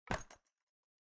箱子上锁.ogg